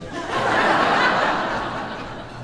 laughter.mp3